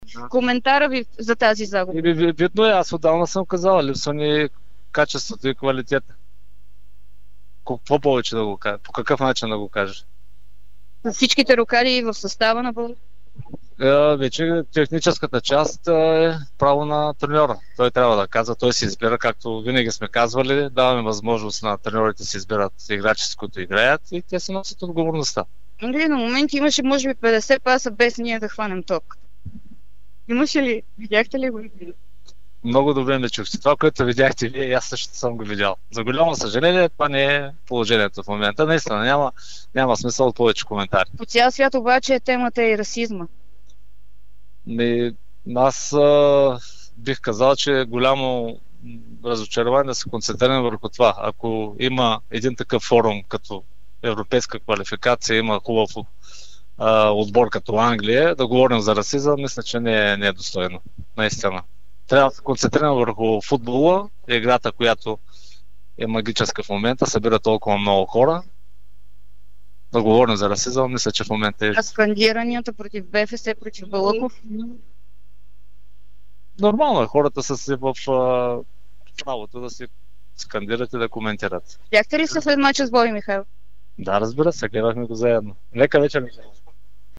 Вицепрезидентът на БФС Йордан Лечков заяви за Дарик радио, че на тима ни липсва качество.